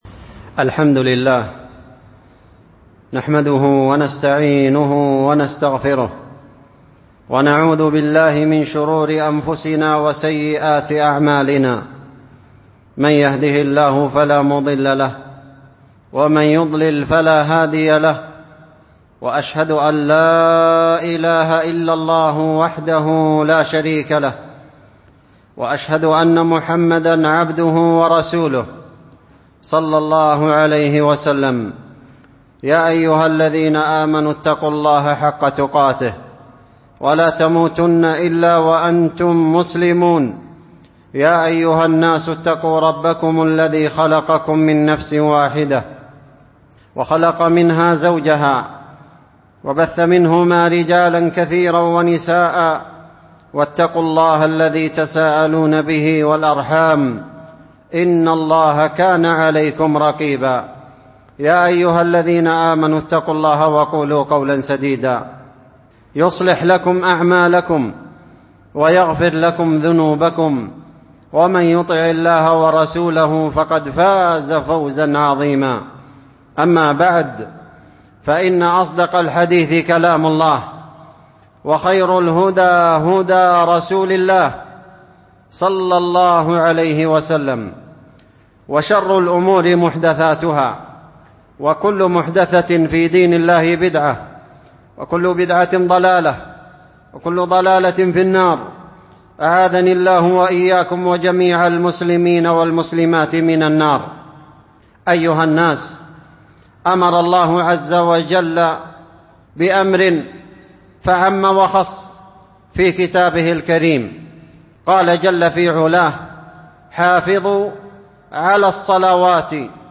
خطبة جمعة في الحث على إقامة صلاة العصر وفضائل صلاة العصر وأهميتها ، وخطورة التخلف عنها وإهمالها